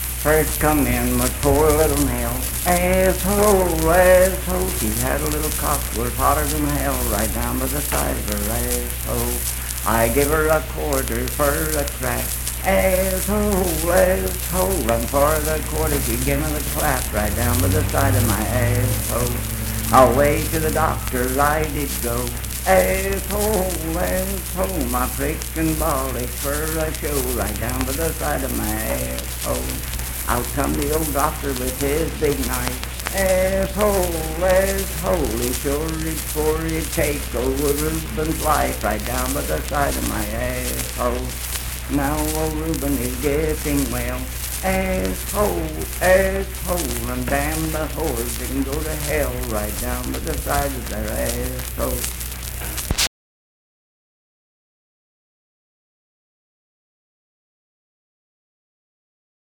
Unaccompanied vocal music performance
Bawdy Songs
Voice (sung)